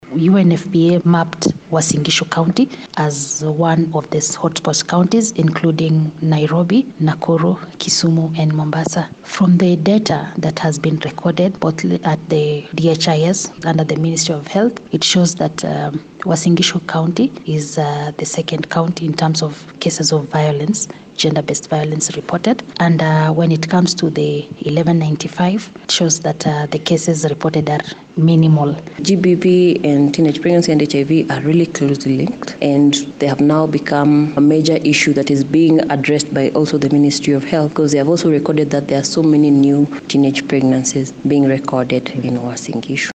SOUND-BITE-UNFPA-ON-GBV.mp3